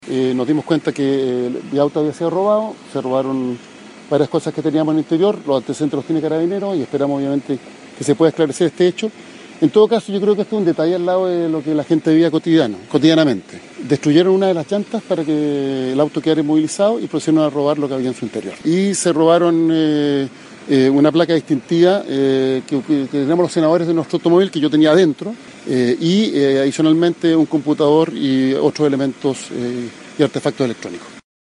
El propio senador Elizalde relató cómo se dio cuenta del robo del cual fue víctima.